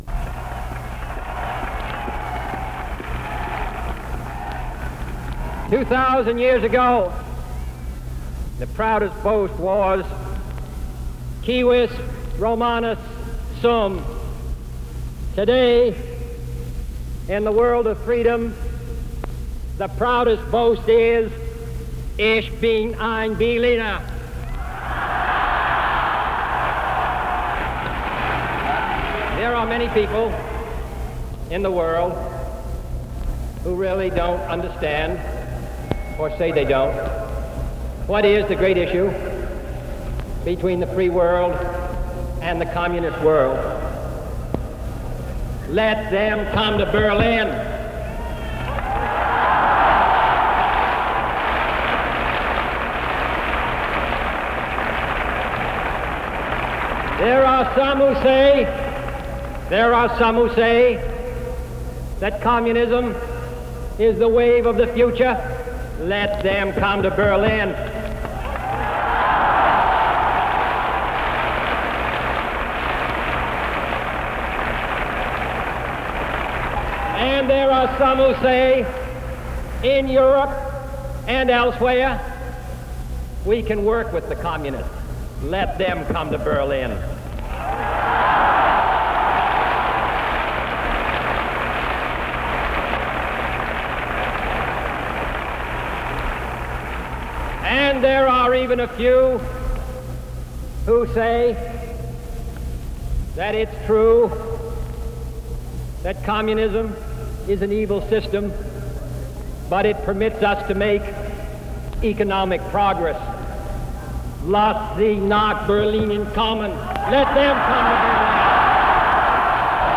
Berlin City Hall speech